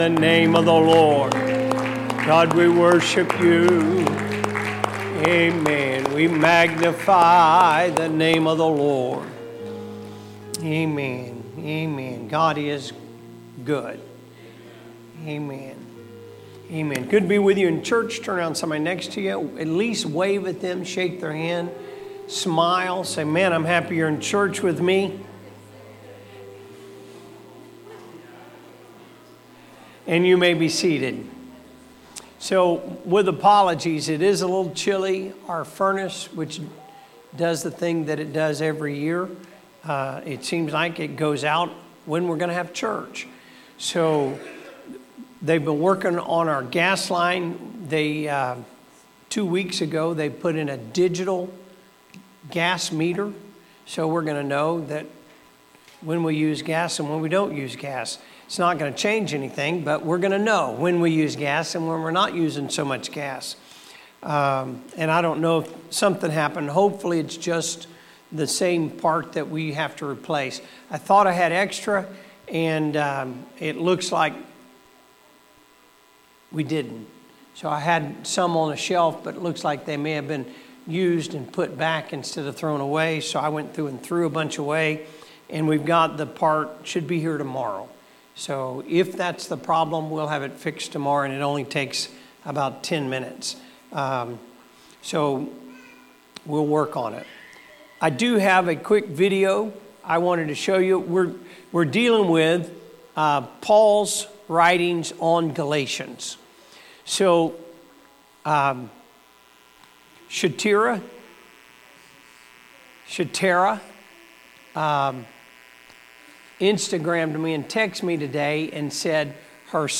Sermons | Elkhart Life Church
Wednesday Service The Folly of Galation Legalism